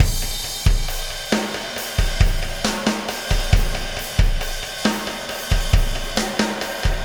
Pulsar Beat 02.wav